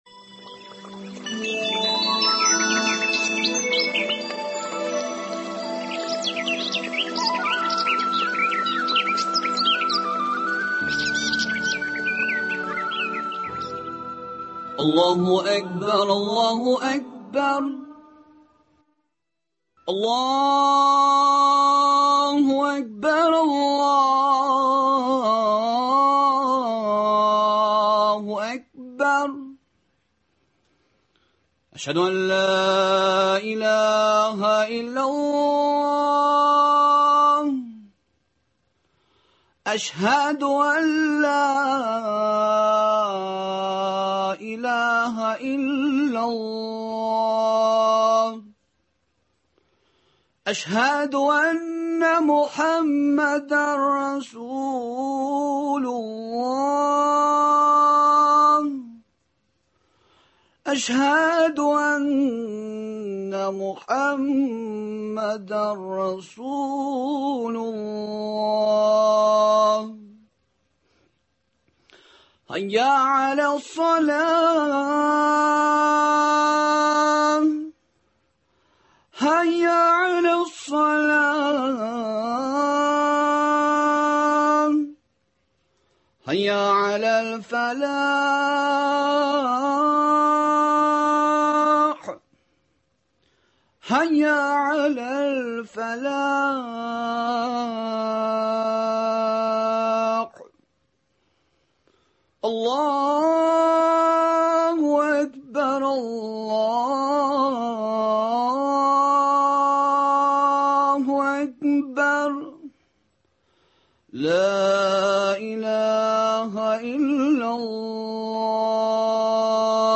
аларның һәм үзебезнең әдәп вә әхлагыбыз турында әңгәмә.